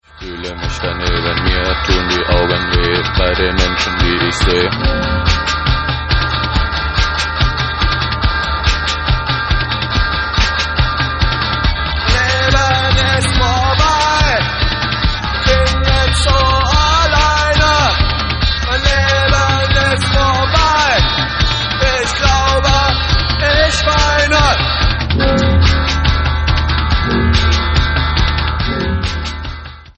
Radikale Sounds der frühen 80er-Jahre.